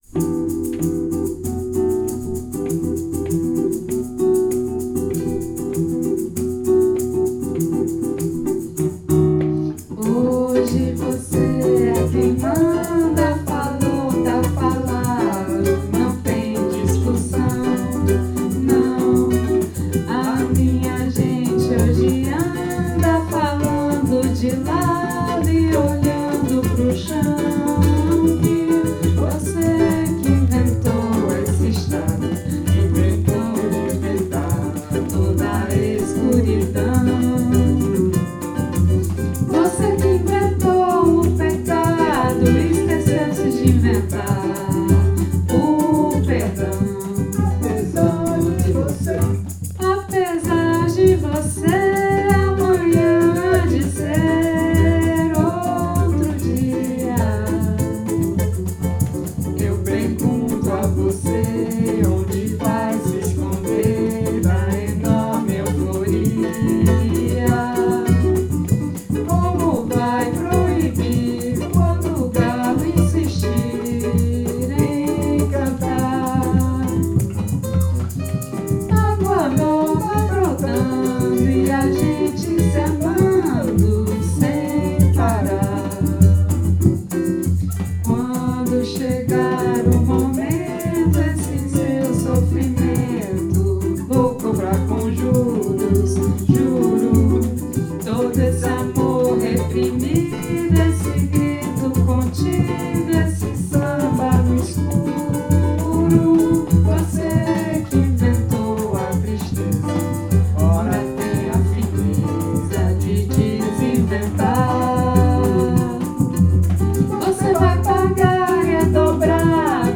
Rec atelier
Em (nouvelle tonalité). Sans clarinette ni pandeiro. Travail sur la mélodie du chant.